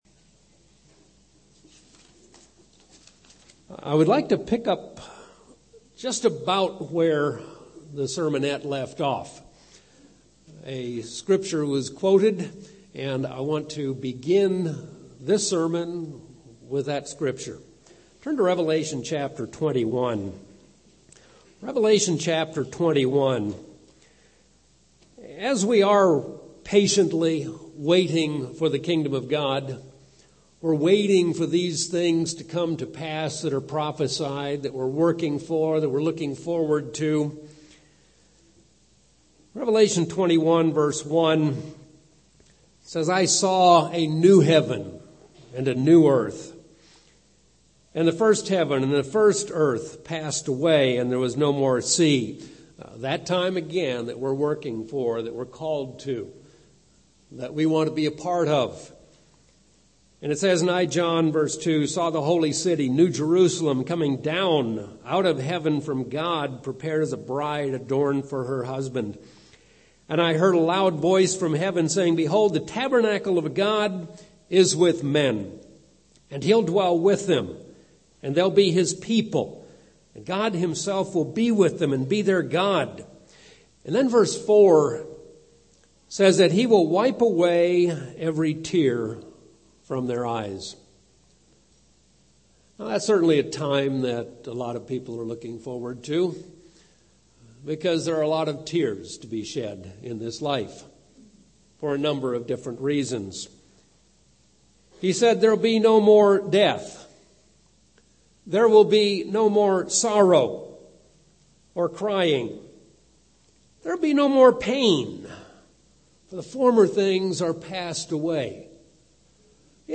This sermon suggests some things to consider when you are trying to help the hurting.